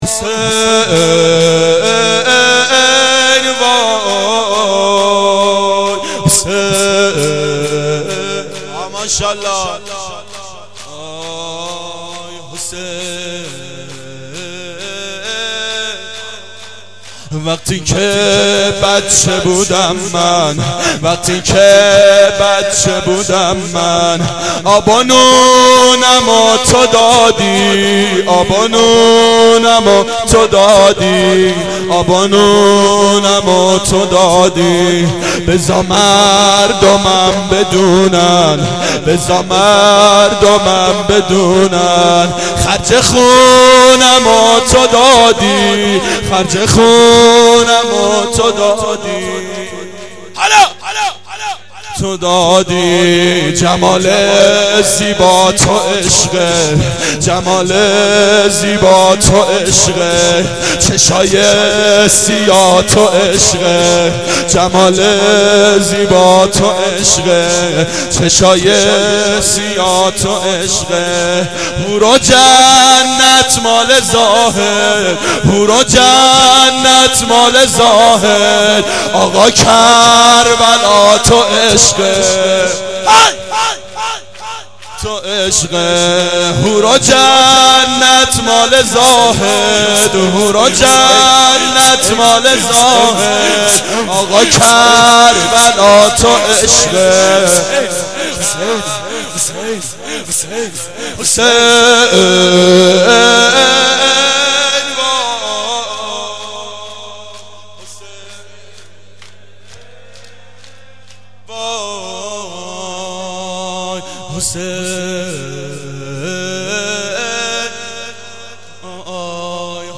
شب اول محرم 89 گلزار شهدای شهر اژیه